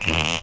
yoshi_snoring1.ogg